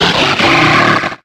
Cries
CRAWDAUNT.ogg